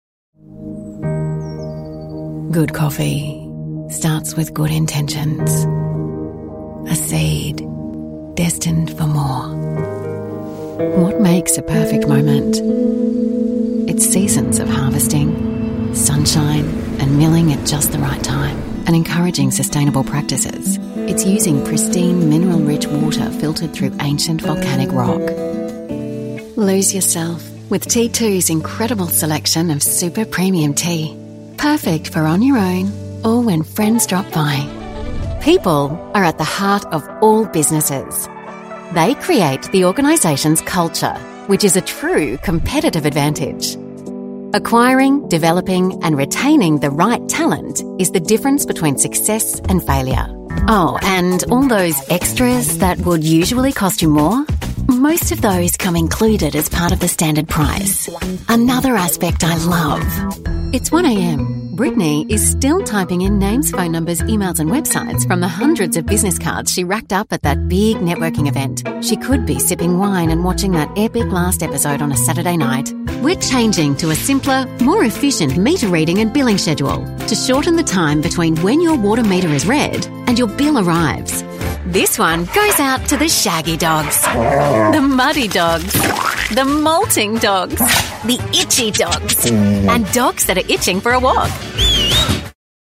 Anglais (Australie)
Commerciale, Naturelle, Polyvalente, Amicale, Chaude
Commercial